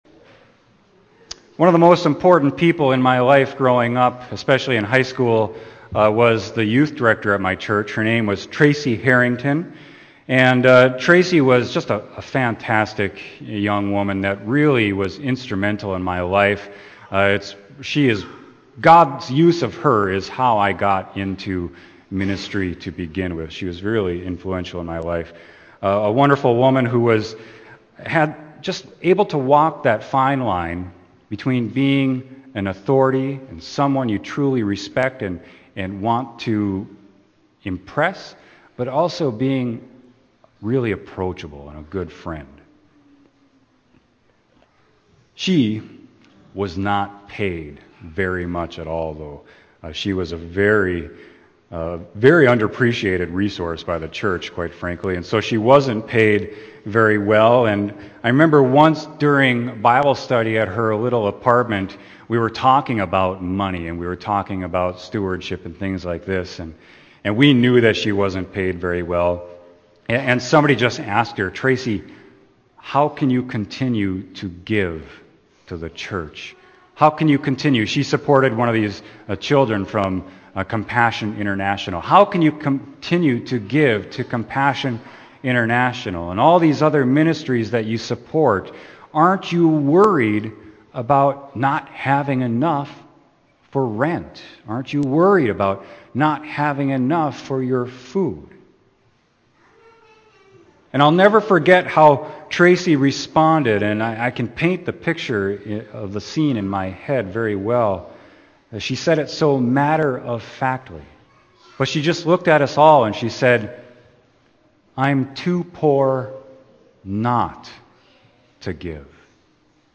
Sermon: Luke 12.22-34